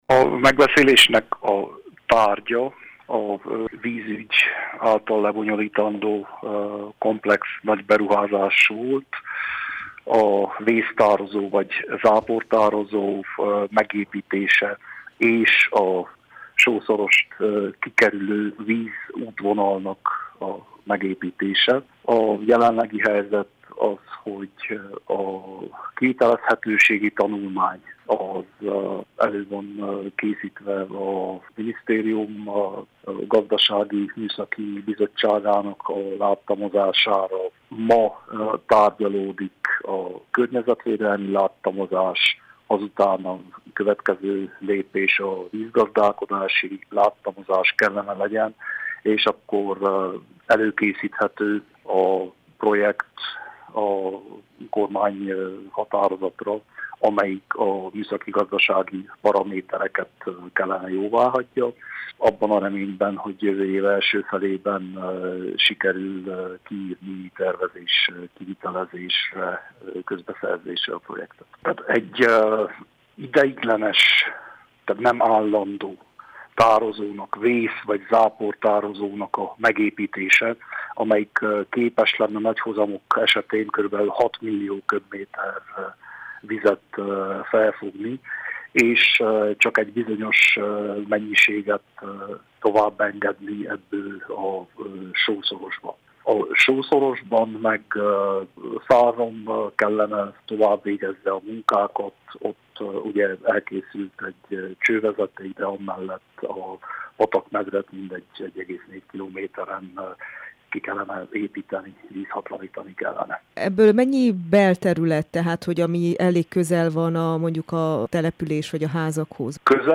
Petres Sándort, Hargita megye prefektusát kérdezte